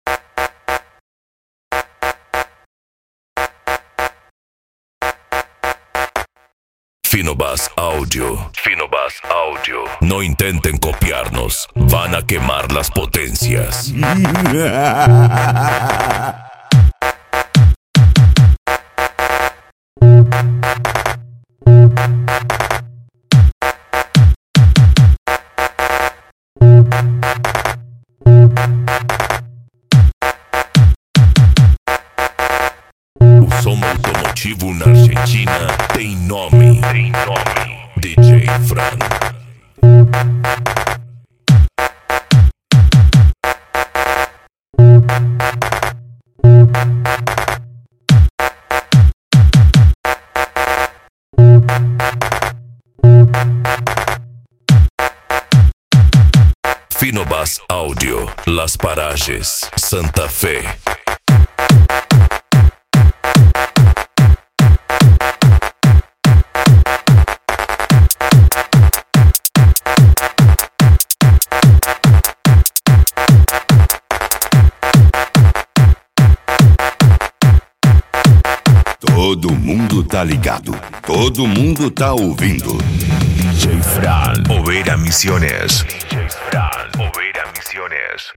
Bass
PANCADÃO